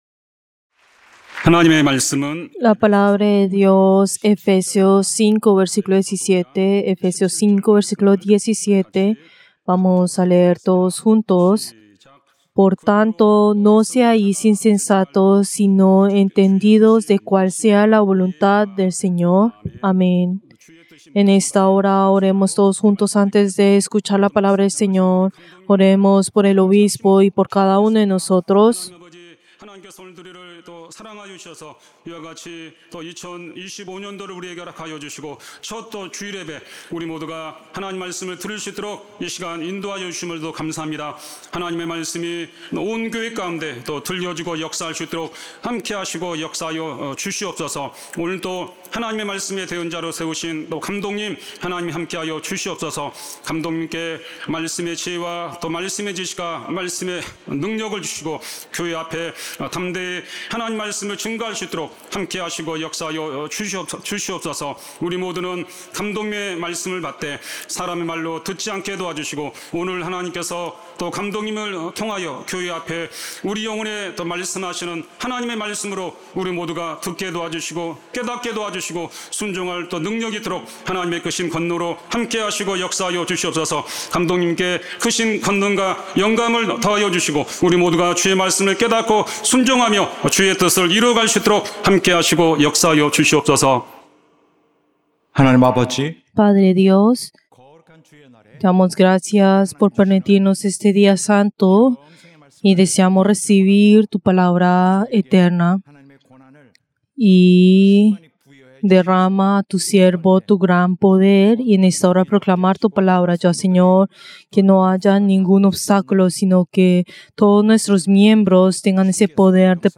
Servicio del Día del Señor del 5 de enero del 2025